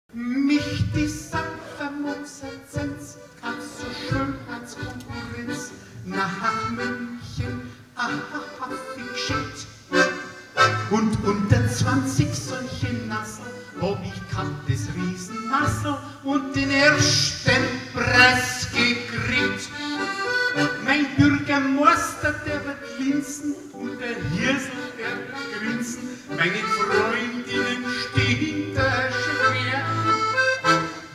Konzert 2004 -Download-Bereich